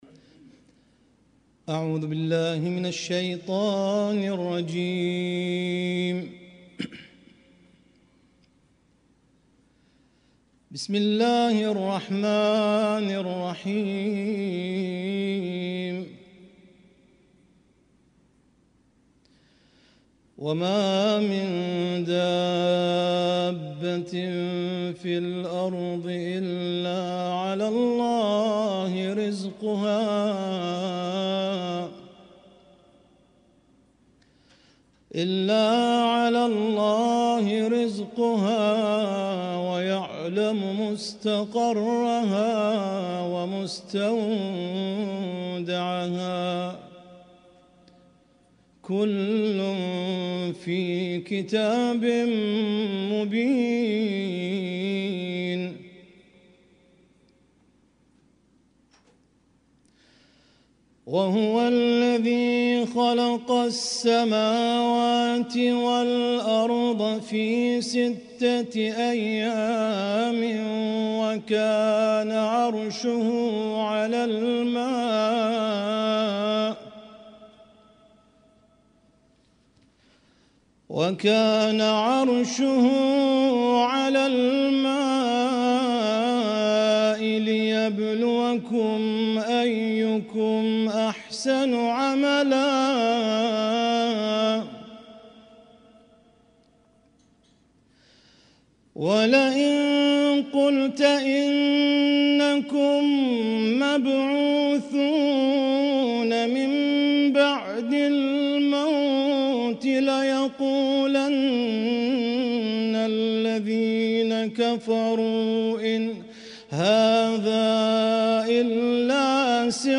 اسم التصنيف: المـكتبة الصــوتيه >> القرآن الكريم >> القرآن الكريم - شهر رمضان 1446